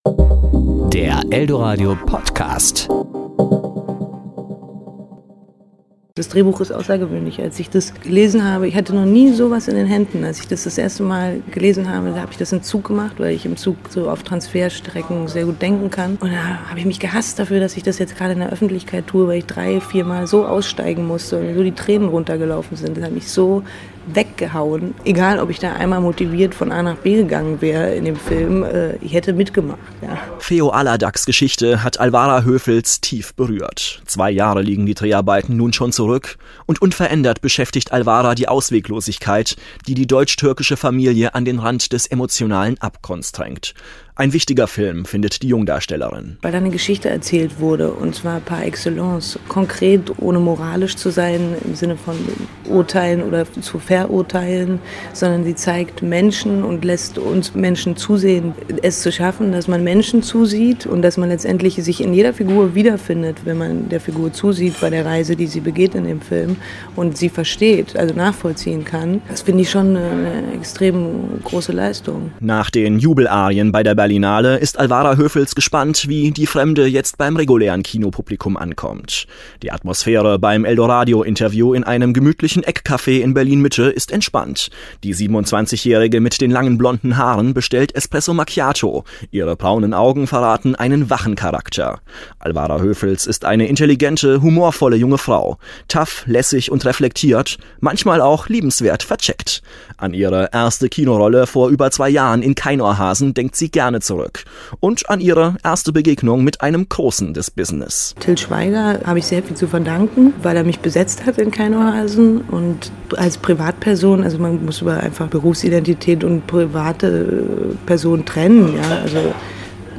Alwara Höfels im Interview